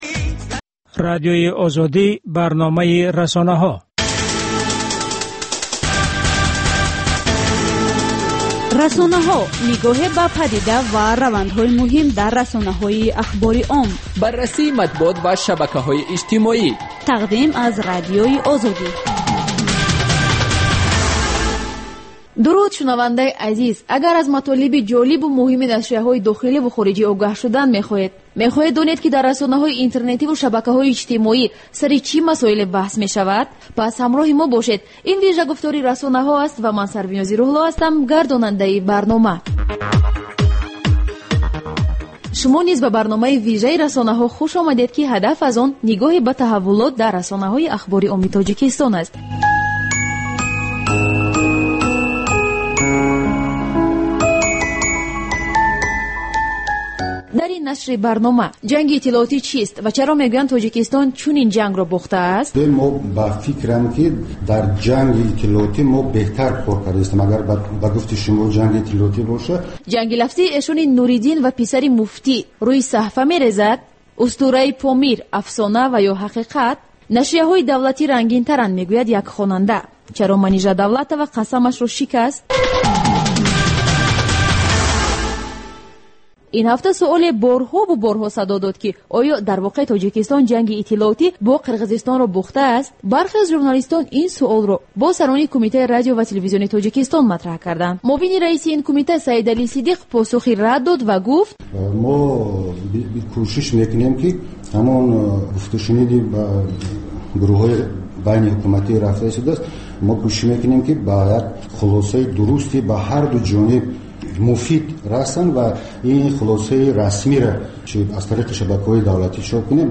Сӯҳбати ошкоро бо чеҳраҳои саршинос ва мӯътабари Тоҷикистон дар мавзӯъҳои гуногун, аз ҷумла зиндагии хусусӣ.